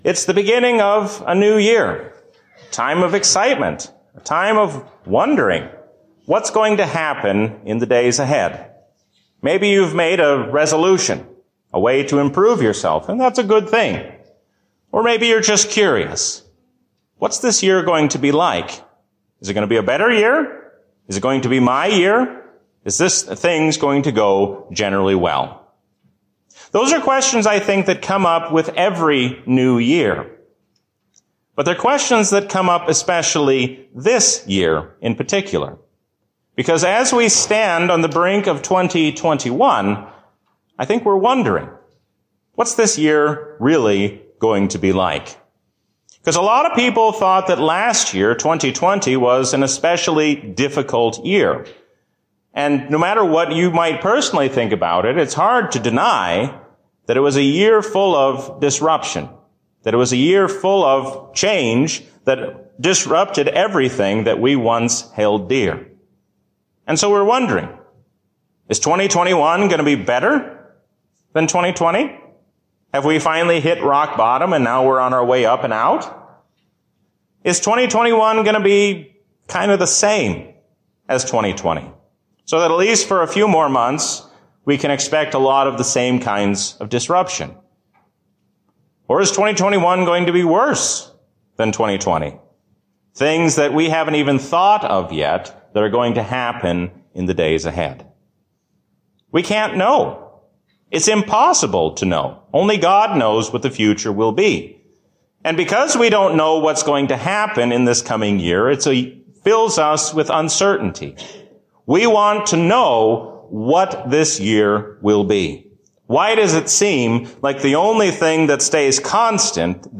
A sermon from the season "Advent 2023." We can trust all the promises of God, because we have God's favor in His Son.